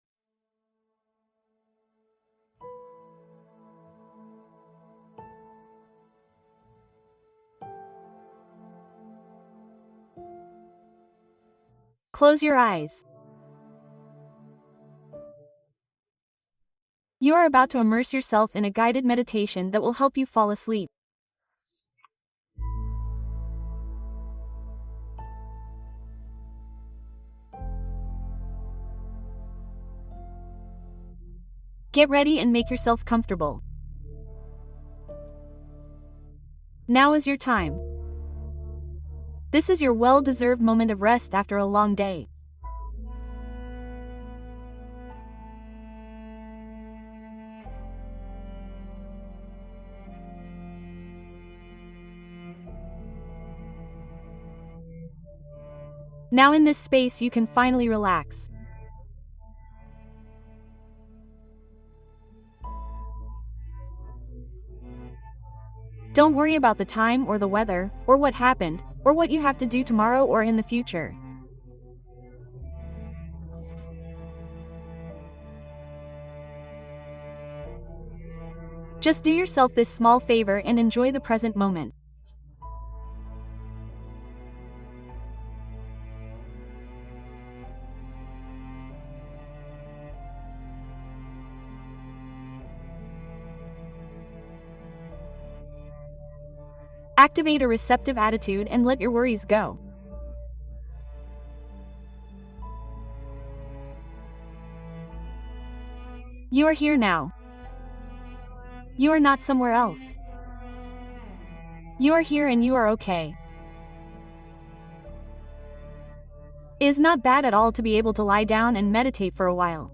Cuento y meditación para un sueño profundamente restaurador